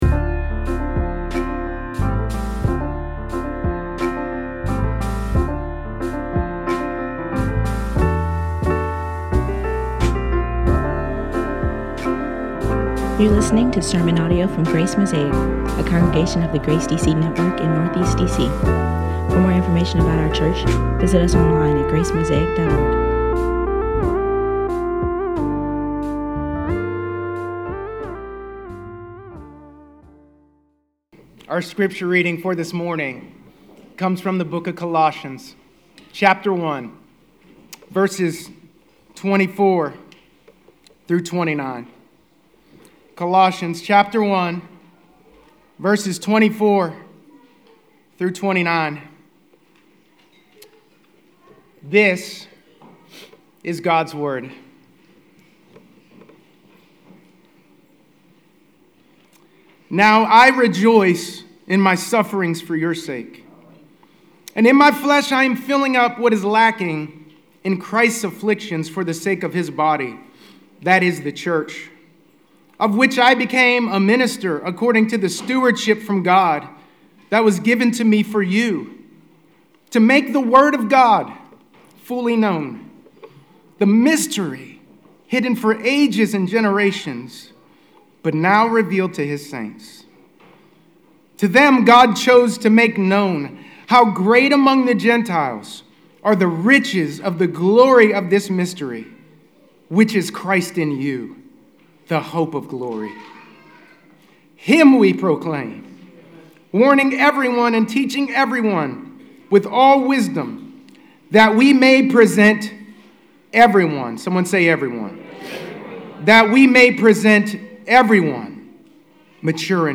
Spring sermon series